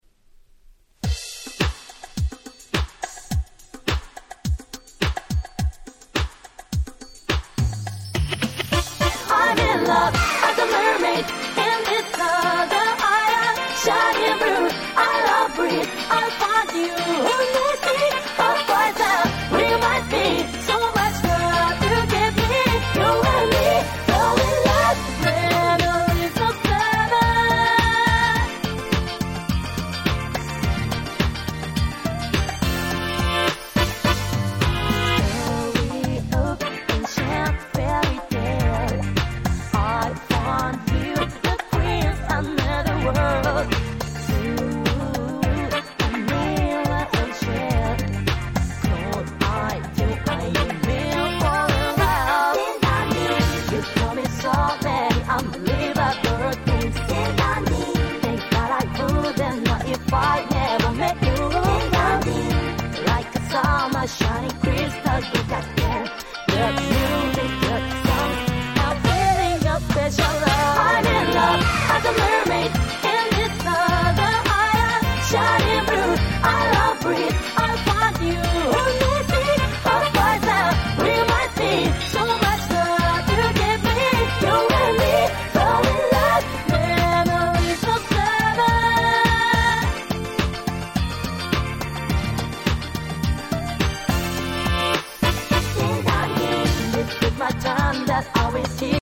問答無用のキャッチーR&B Classics !!!!!